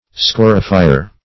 Meaning of scorifier. scorifier synonyms, pronunciation, spelling and more from Free Dictionary.
Search Result for " scorifier" : The Collaborative International Dictionary of English v.0.48: Scorifier \Sco"ri*fi`er\, n. (Chem.)